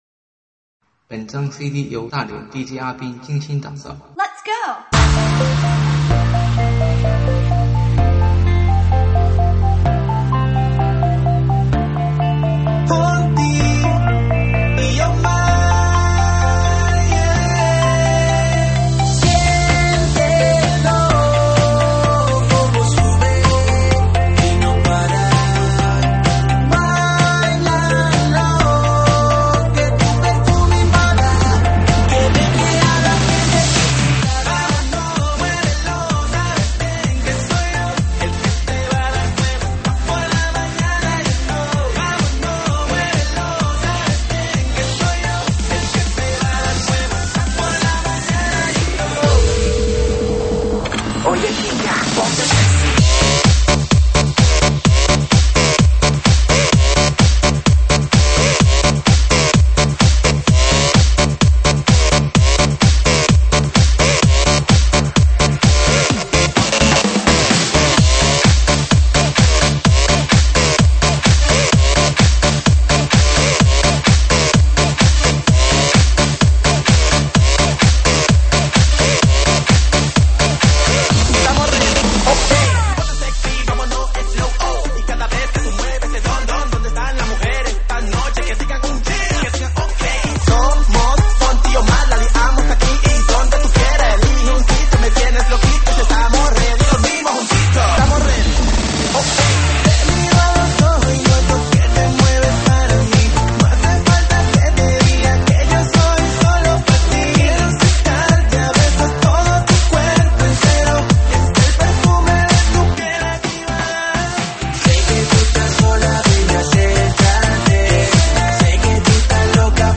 3D全景环绕